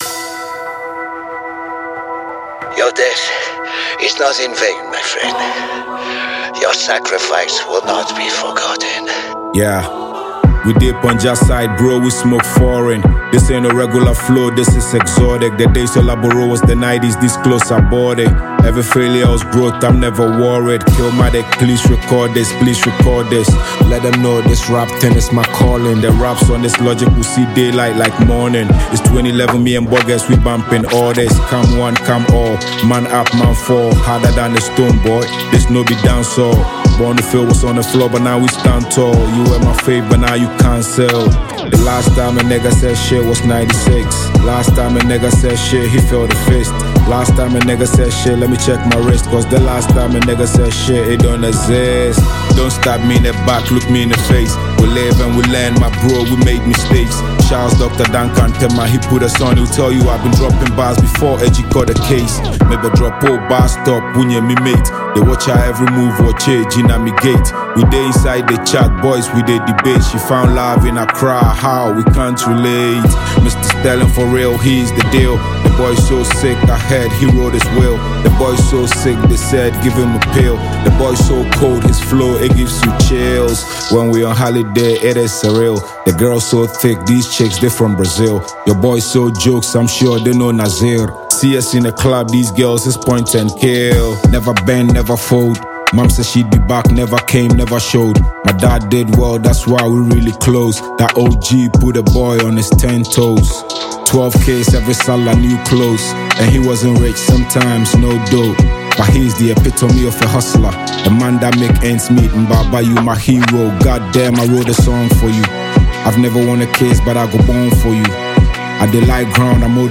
rap-filled track